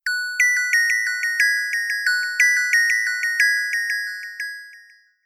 notification_one.mp3